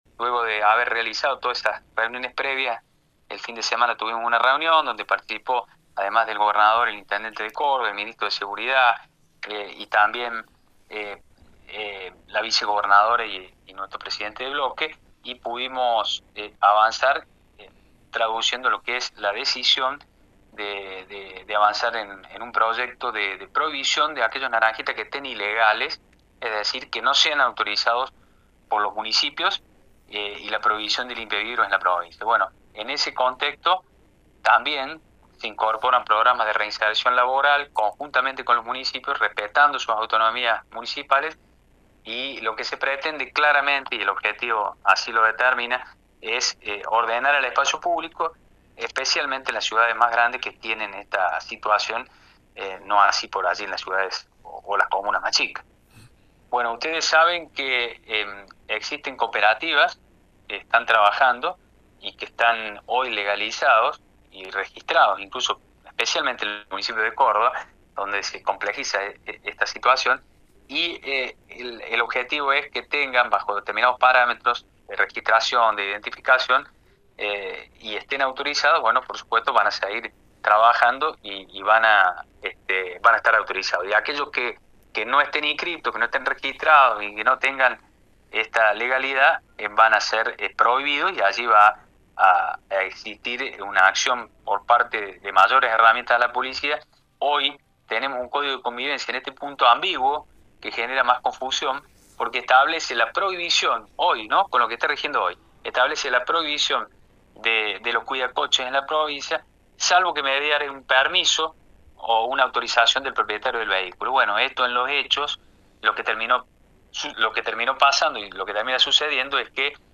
El legislador del oficialismo, Juan Manuel Llamosas, explicó sobre la iniciativa elaborada por el gobierno provincial y señaló que además se han previsto programas de reinserción laboral.